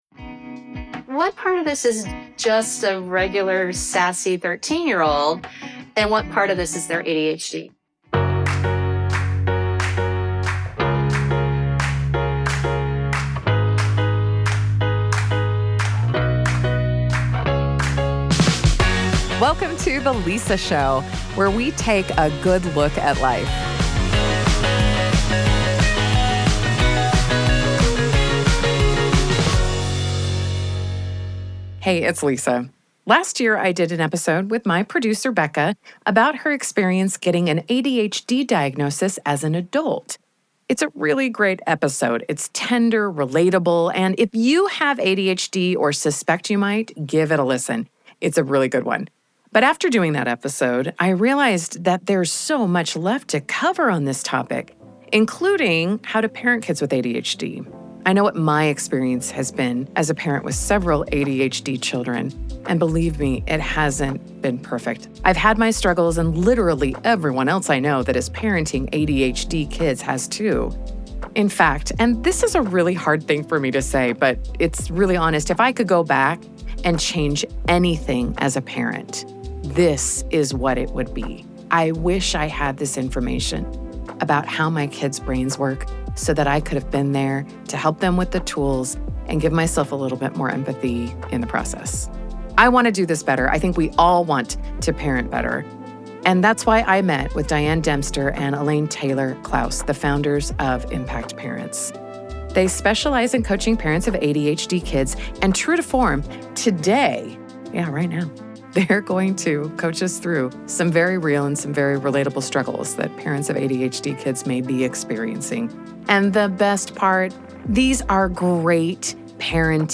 Whether you’re raising a child with ADHD or just trying to show up better as a parent, this conversation will leave you feeling more informed, compassionate, and empowered.